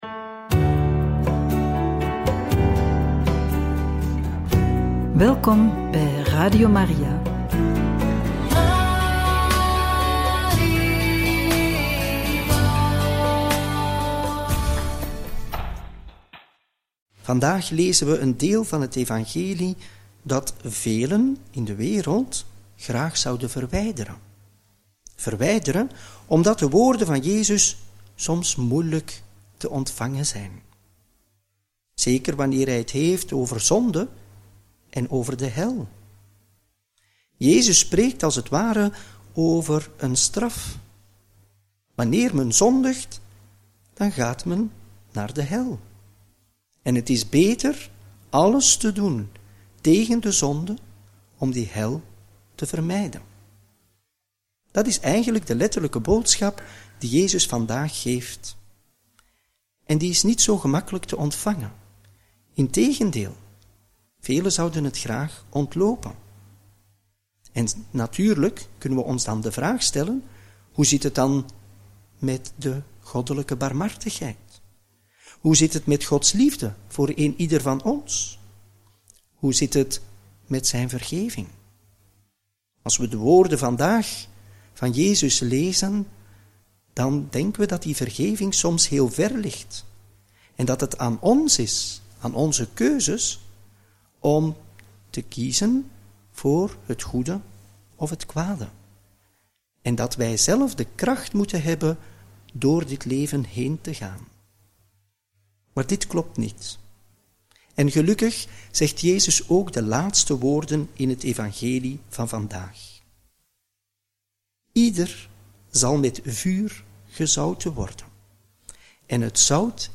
Homilie bij het Evangelie van donderdag 27 februari 2025 – Mc 9, 41-50